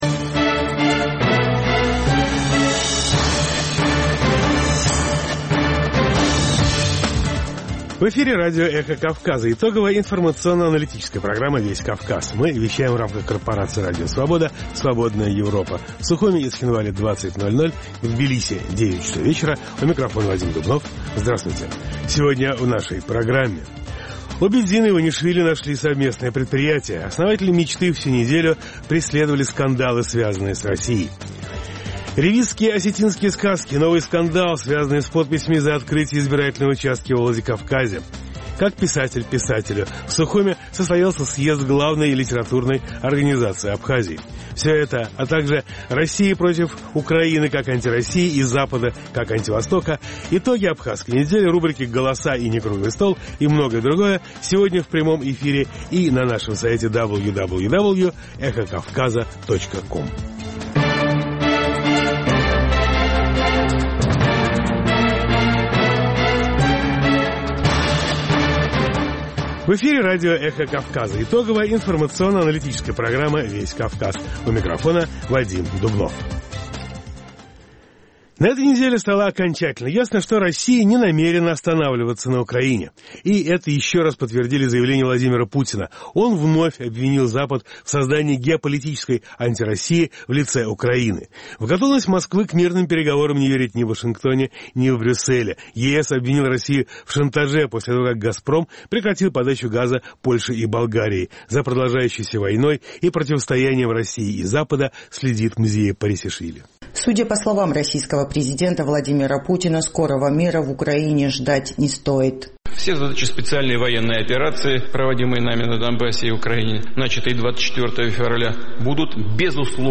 Новости, репортажи с мест, интервью с политиками и экспертами, круглые столы, социальные темы, международная жизнь, обзоры прессы, история и культура.